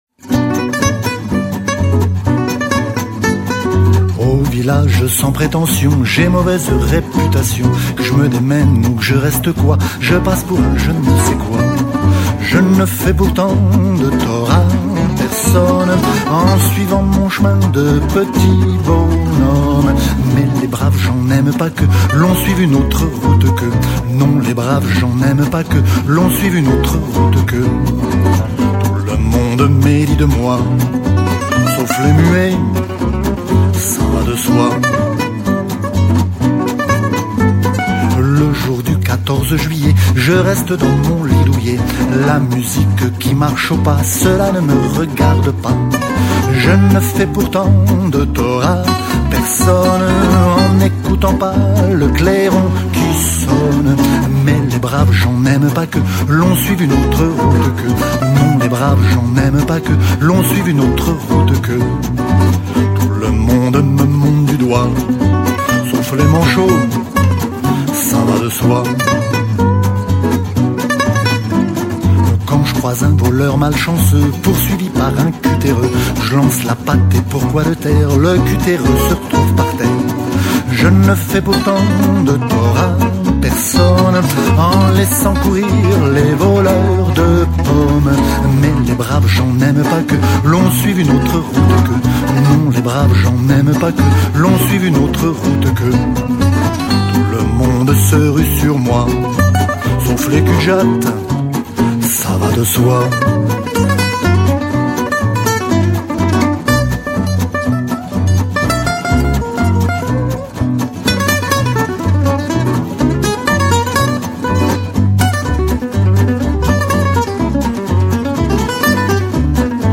entraîne sans difficulté dans son univers manouche.